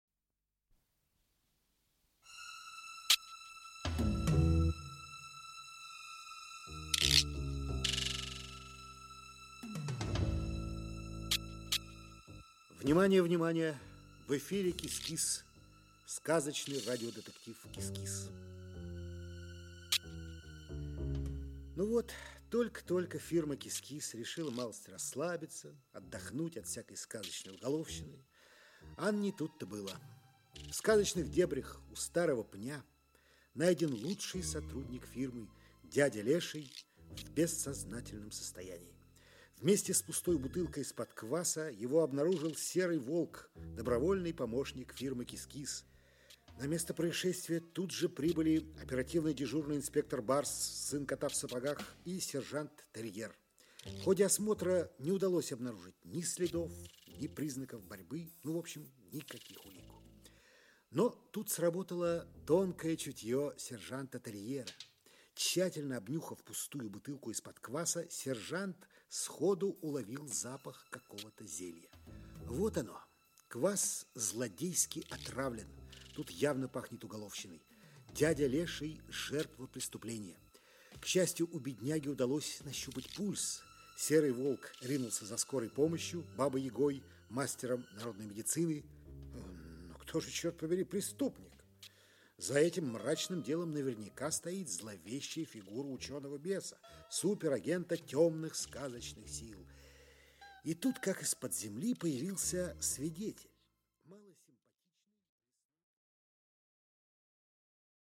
Аудиокнига КИС-КИС. Дело № 10. Часть 2 | Библиотека аудиокниг